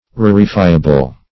Search Result for " rarefiable" : The Collaborative International Dictionary of English v.0.48: Rarefiable \Rar"e*fi`a*ble\ (r[a^]r"[-e]*f[imac]`[.a]*b'l), a. [Cf. F. rar['e]fiable.] Capable of being rarefied.